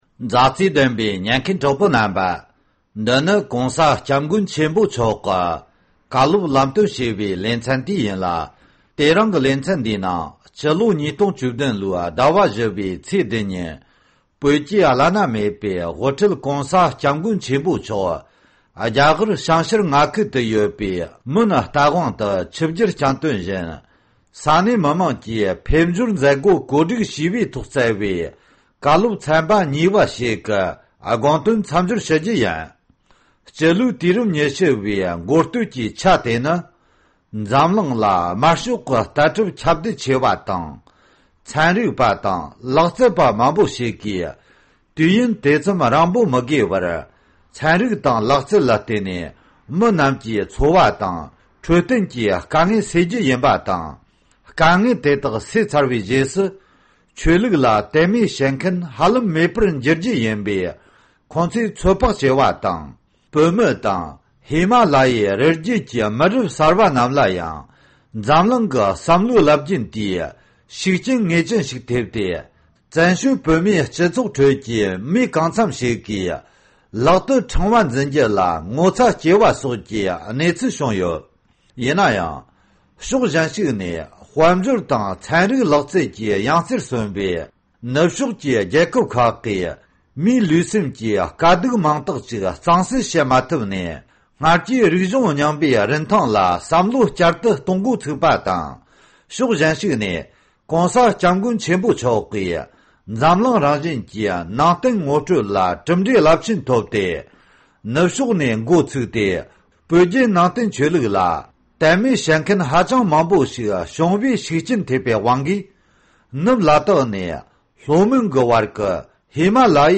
ཞིབ་ཕྲ་འདི་གའི་གསར་འགོད་པས་ས་གནས་ནས་གནས་ཚུལ་བཏང་བར་གསན་རོགས།